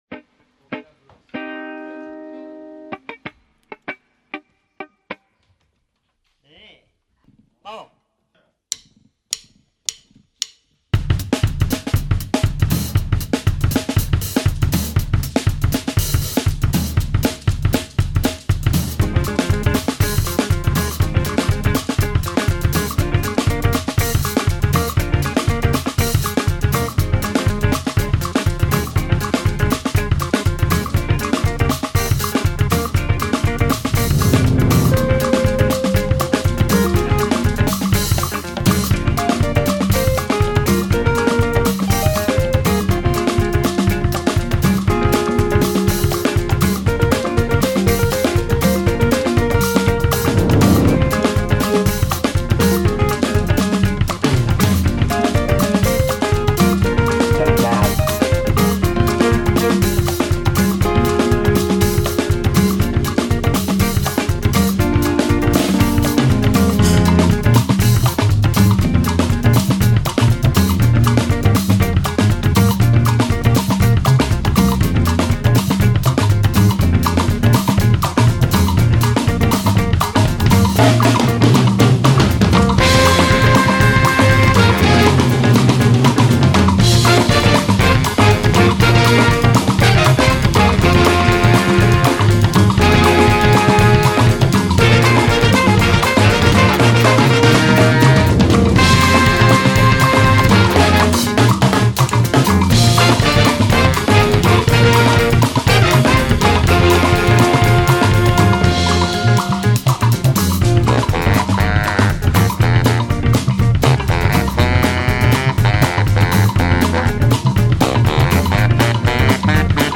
🎙 Podcast – Intervista a Seun Kuti
Artista-a-la-Vista-Intervista-Seun-Kuti.mp3